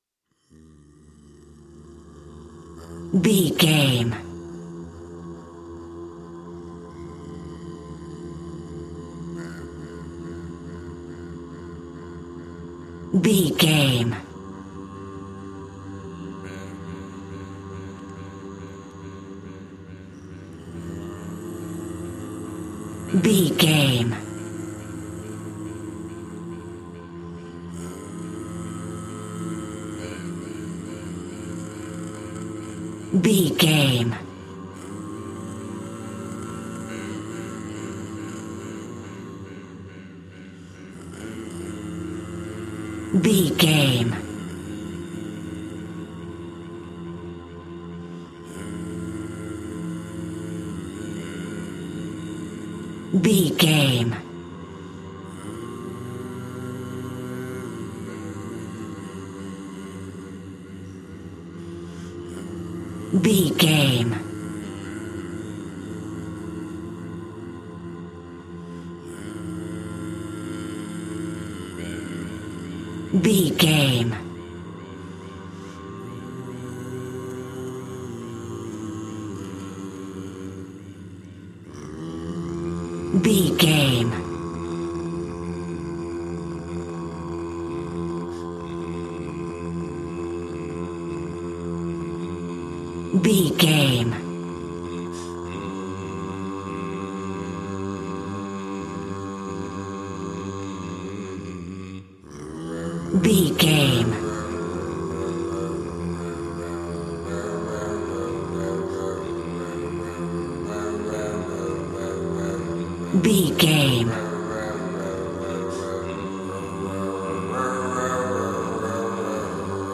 Aeolian/Minor
D
groovy